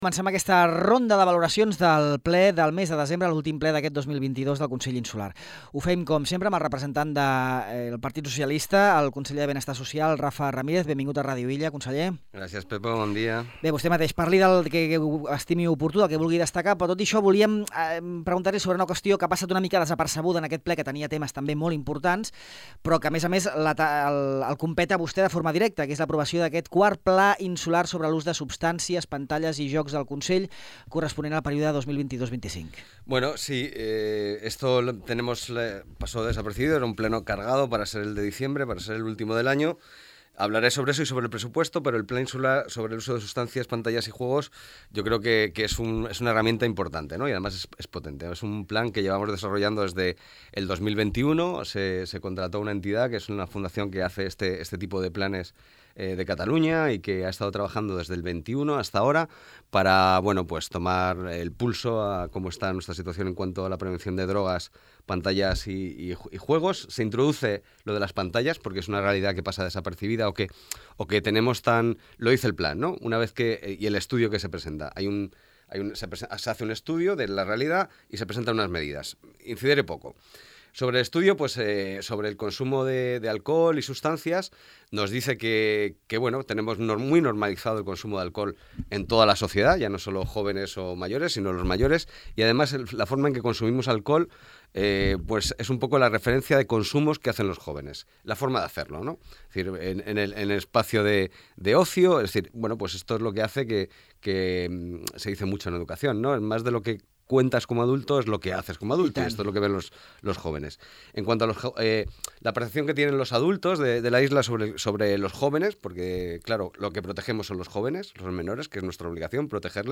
Rafa Ramírez, del PSOE; Llorenç Córdoba, de Sa Unió; i Bartomeu Escandell, de GxF; exposen els seus punts de vista sobre el Ple ordinari del mes de desembre que, entre altres punts, ha aprovat inicialment el pressupost insular de 2023, l’estudi de viabilitat del nou contracte del servei públic d’autobús, el nou Pla insular sobre l’ús de substàncies, pantalles i jocs, i instar al Govern balear a què remodeli l’emissari de l’Estació Depuradora d’Aigües Residuals (EDAR) de Formentera.